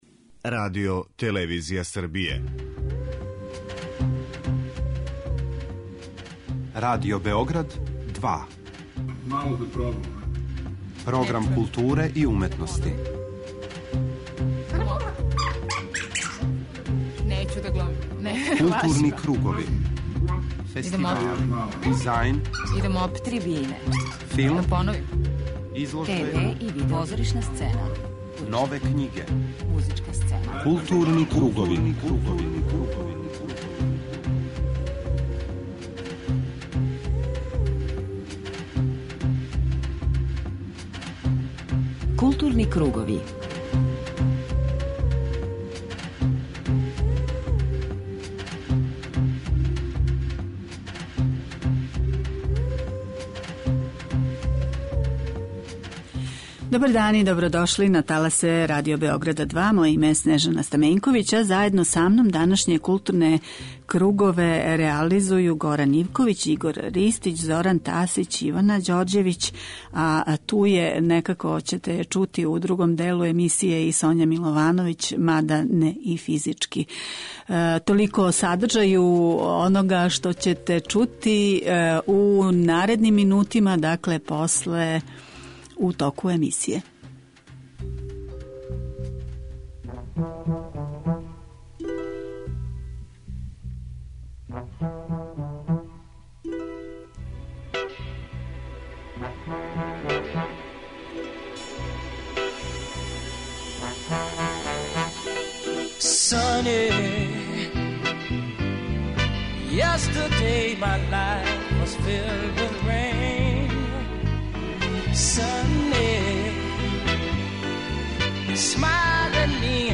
чућете разговоре